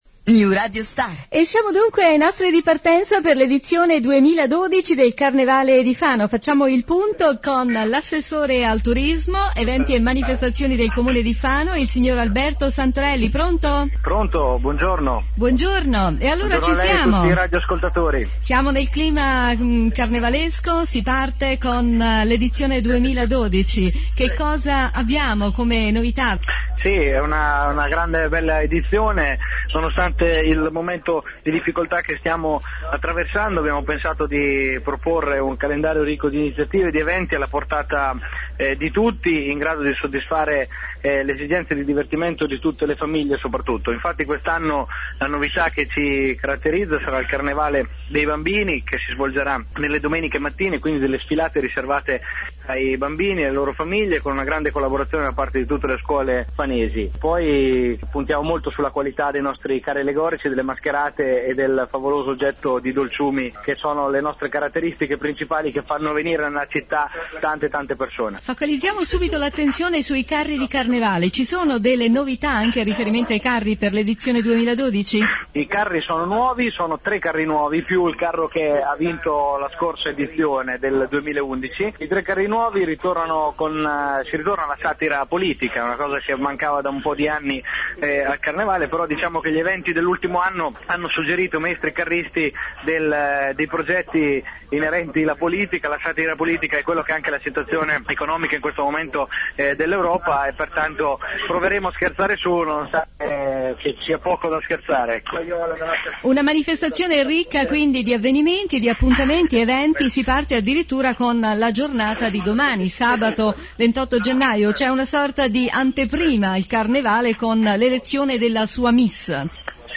Ascolta intervista Alberto Santorelli Assessore Turismo-Eventi
intervista-Alberto-Santorelli-Assessore-Turismo-Eventi.mp3